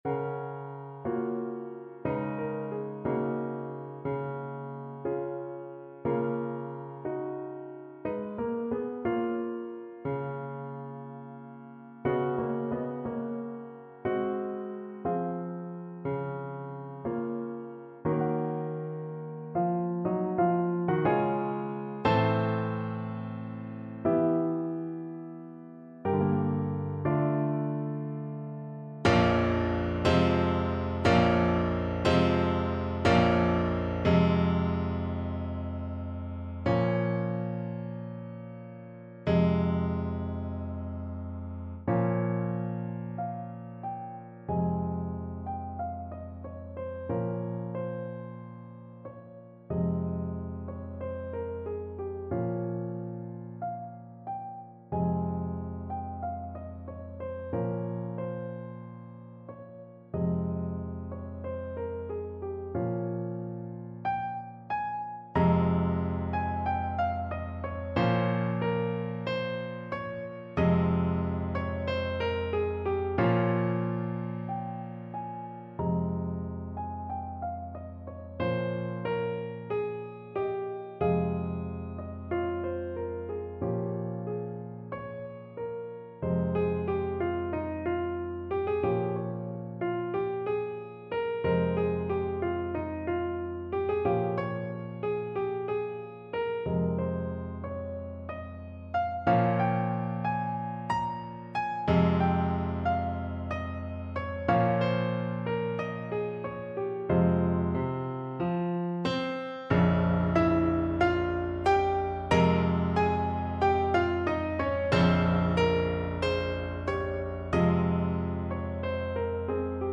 Lento
Classical (View more Classical Soprano Voice Music)